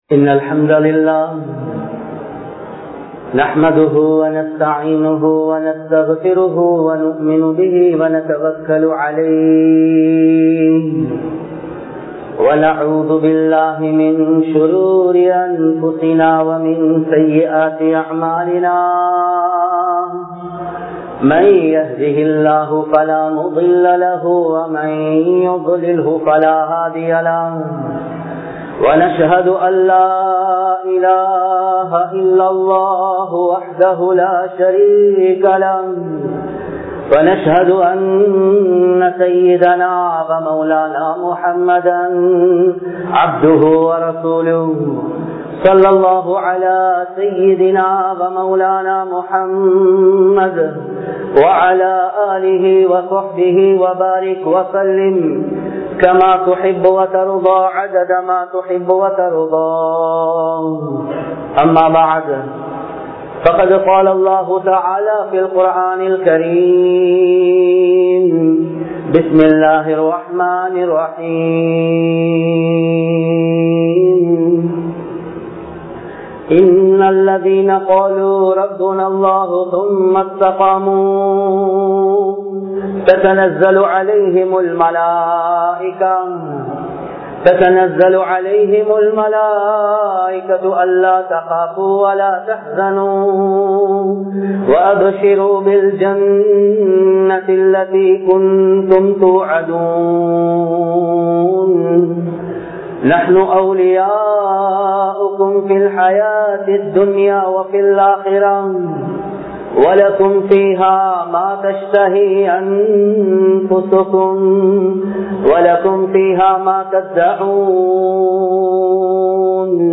Muslimkal Oru Ummath Aahum (முஸ்லிம்கள் ஒரு உம்மத் ஆகும்) | Audio Bayans | All Ceylon Muslim Youth Community | Addalaichenai